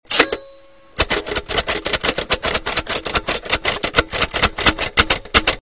Heavy-Duty Braille Typewriter, Built Like a Tank
perkins_brailler.mp3